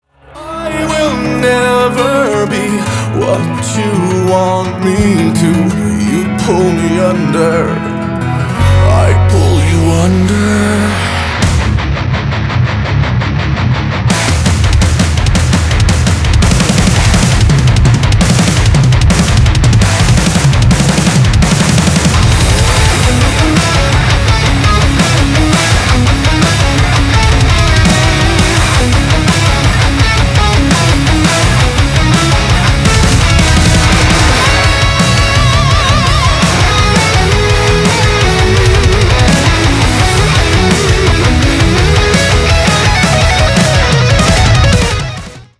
Kategória: Rock